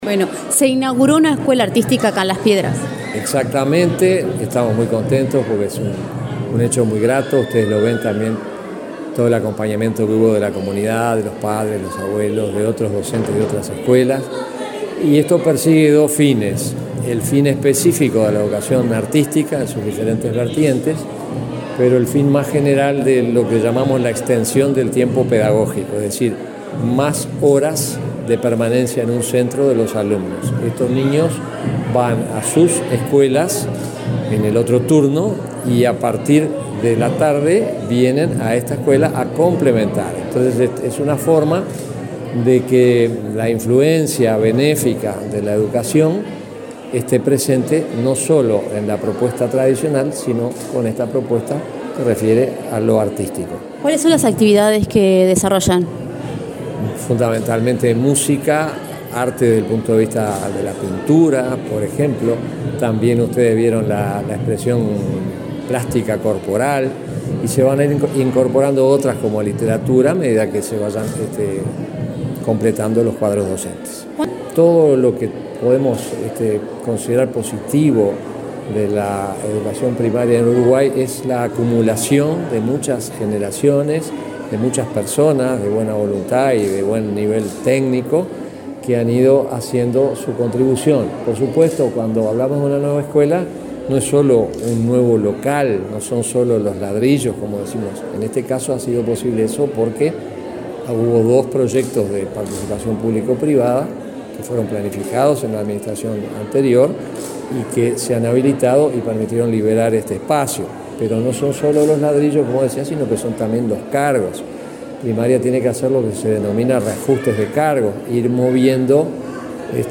Declaraciones del presidente interino de la ANEP, Juan Gabito Zóboli
Declaraciones del presidente interino de la ANEP, Juan Gabito Zóboli 28/11/2023 Compartir Facebook X Copiar enlace WhatsApp LinkedIn El presidente interino de la ANEP, Juan Gabito Zóboli, dialogó con Comunicación Presidencial en Canelones, luego de participar de la inauguración de la escuela n.º 317 de educación artística de Las Piedras.